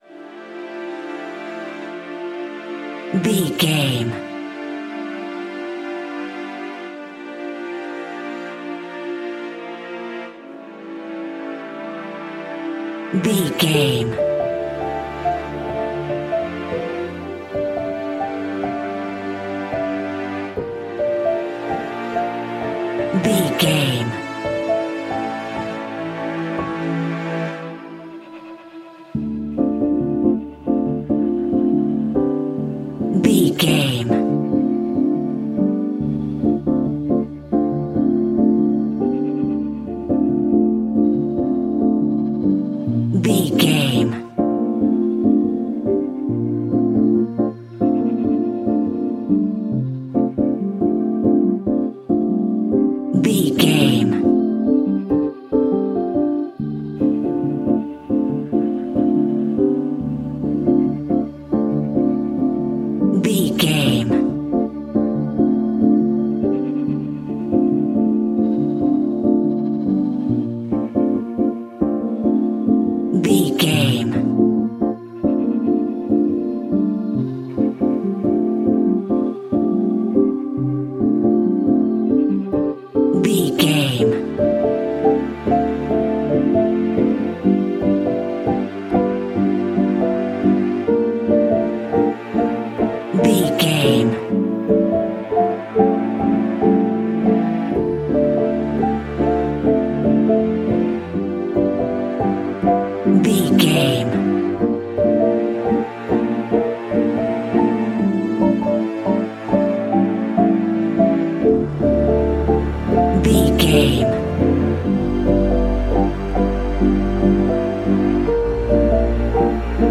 Aeolian/Minor
Slow
cello
double bass
violin
piano
strings
vocals